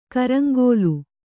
♪ karaŋgōlu